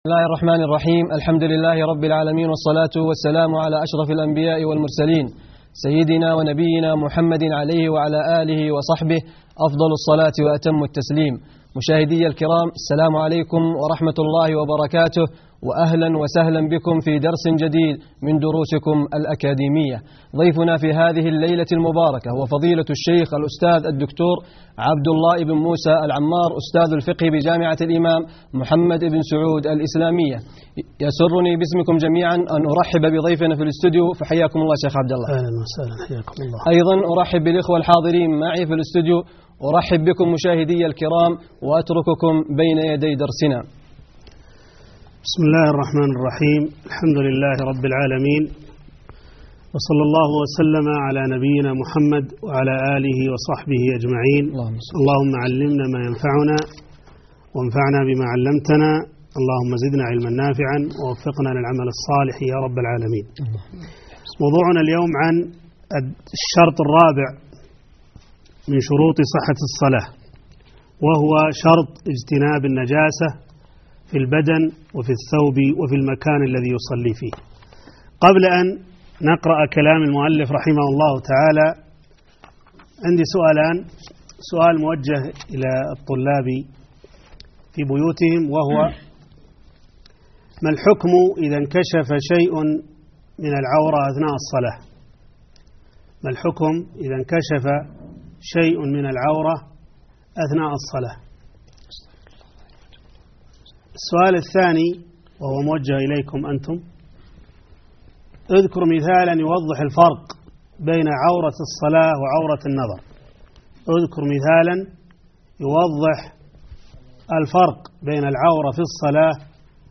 الدرس الخامس عشر _ تابع شروط صحة الصلاة _ اجتناب النجاسة